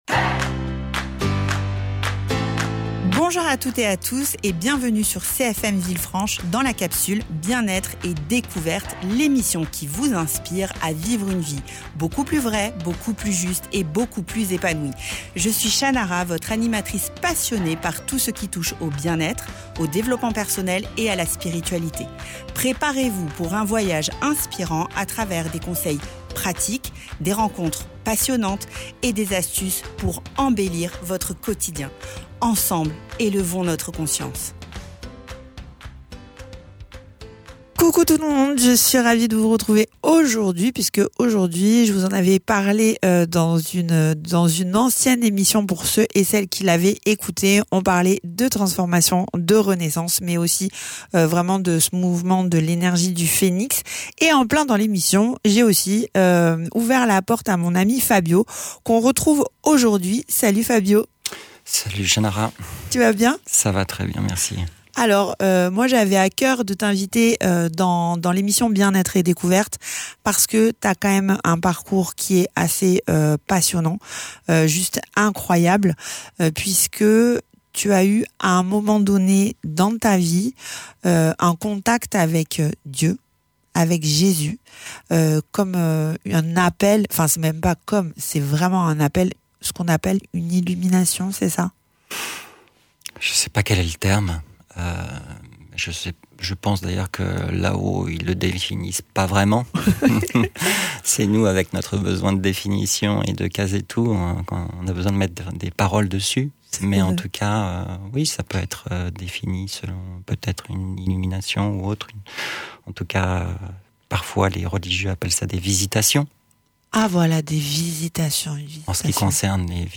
Dans cette nouvelle émission, j’ai le privilège d’accueillir un invité au parcours de vie fort et inspirant. Issu de la tradition juive, il nous partage aujourd’hui son cheminement spirituel, marqué par une rencontre personnelle avec Jésus-Christ qui a profondément transformé sa vie.